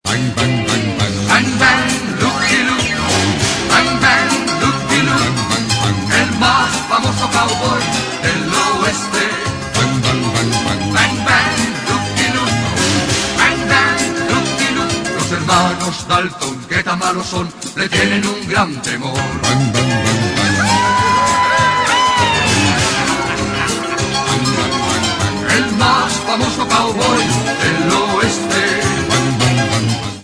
Melodía de la serie de dibujos animados